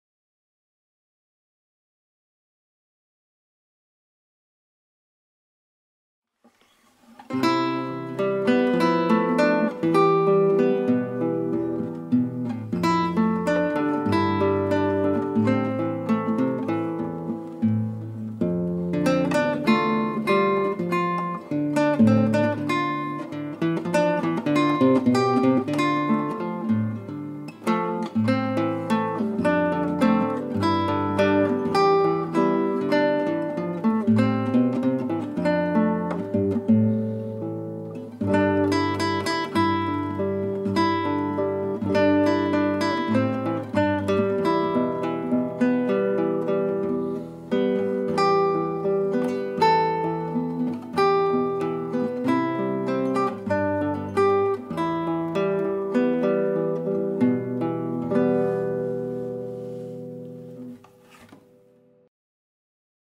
Игра на гитаре